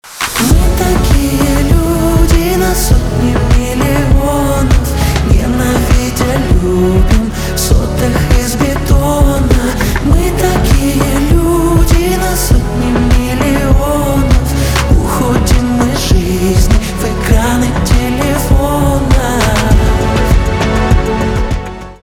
поп
грустные , чувственные , битовые , красивые